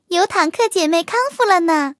卡尔臼炮修理完成提醒语音.OGG